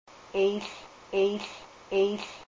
a native speaker of the Kasaan dialect of Alaskan Haida.